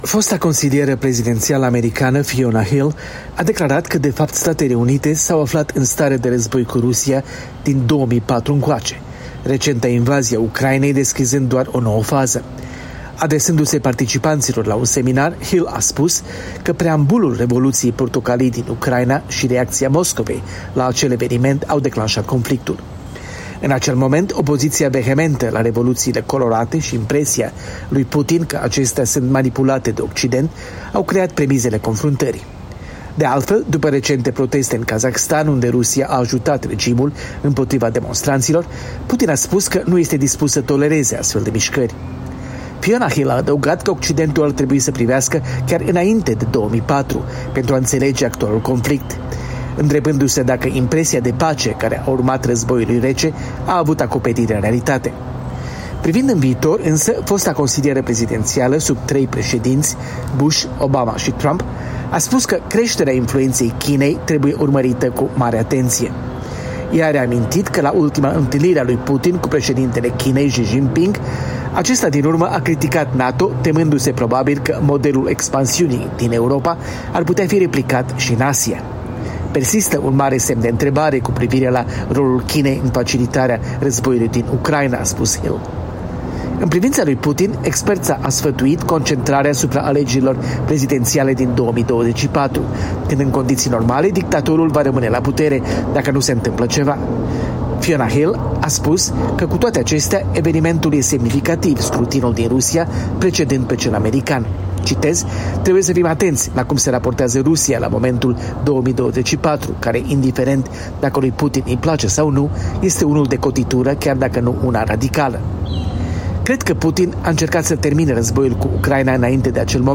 Fosta consilieră prezidențială americană Fiona Hill a declarat că Statele Unite s-au aflat, de fapt, în stare de război cu Rusia din 2004 încoace, recenta invazie a Ucrainei deschizând doar o nouă fază. Adresându-se participanților la un seminar, Hill a spus că preambulul Revoluției Portocalii din Ucraina și reacția Moscovei la acel eveniment au declanșat conflictul.